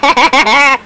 Boy Laughing Sound Effect
Download a high-quality boy laughing sound effect.
boy-laughing.mp3